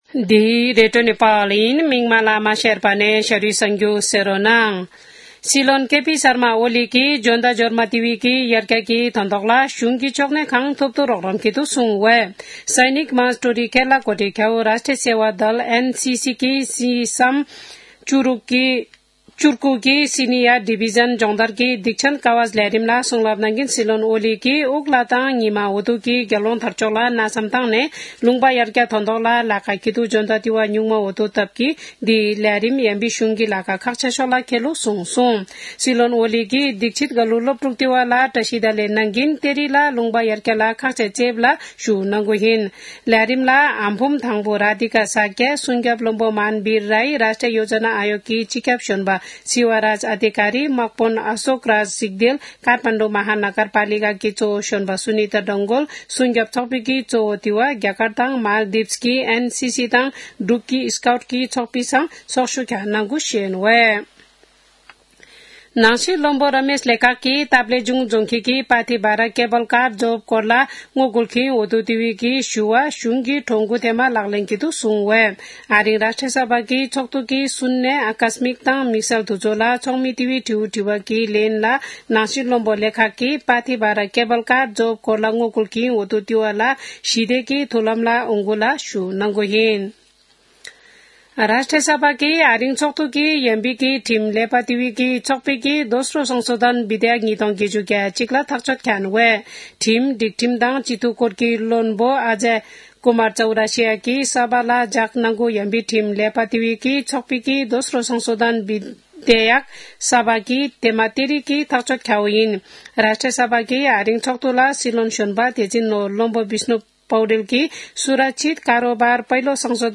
शेर्पा भाषाको समाचार : १९ फागुन , २०८१
sharpa-news-.mp3